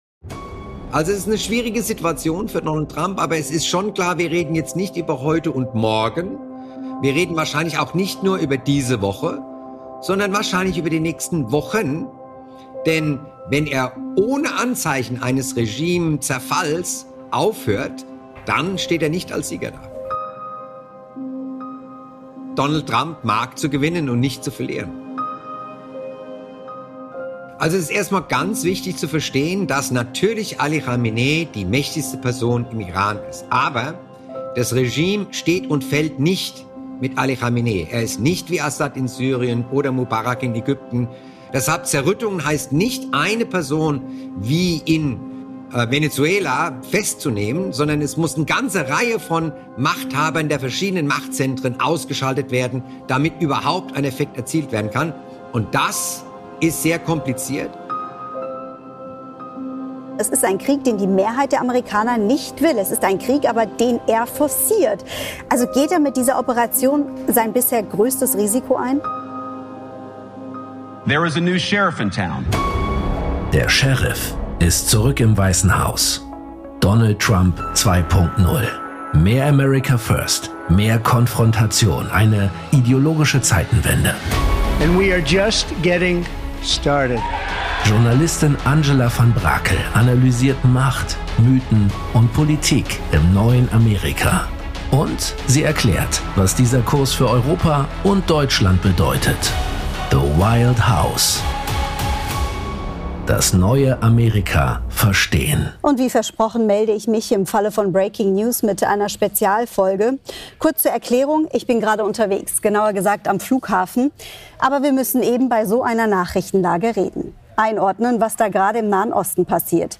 spricht in dieser Spezialfolge mit dem Nahost-Experten